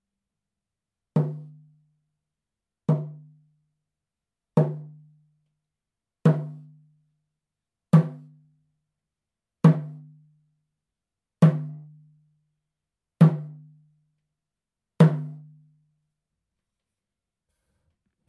ネイティブ アメリカン（インディアン）ドラム NATIVE AMERICAN (INDIAN) DRUM 12インチ（elk アメリカアカシカ・ワピチ）
ネイティブアメリカン インディアン ドラムの音を聴く
乾いた張り気味の音です